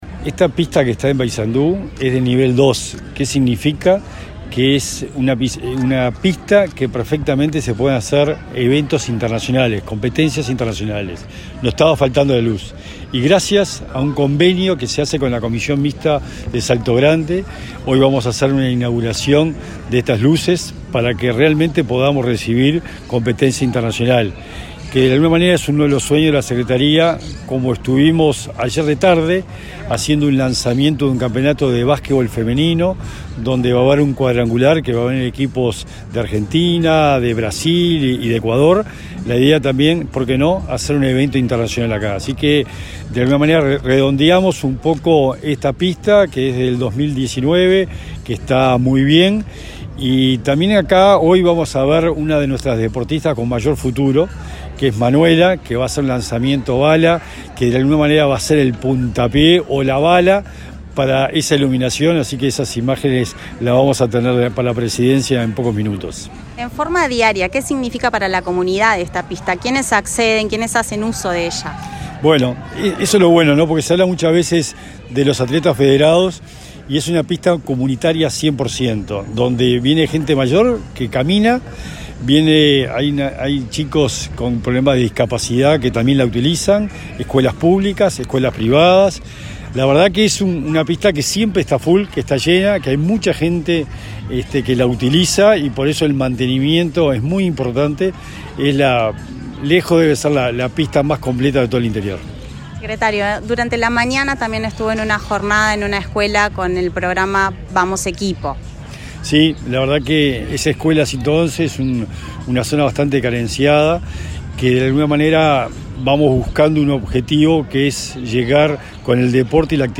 Entrevista a secretario nacional del Deporte, Sebastián Bauzá
Entrevista a secretario nacional del Deporte, Sebastián Bauzá 26/05/2022 Compartir Facebook X Copiar enlace WhatsApp LinkedIn Tras el acto de inauguración de luminaria de pista sintética de atletismo ubicada en la plaza de deportes José Enrique Rodó, del departamento de Paysandú, este 26 de mayo, Bauzá brindó declaraciones a Comunicación Presidencial.